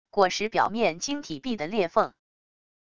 果实表面晶体壁的裂缝wav音频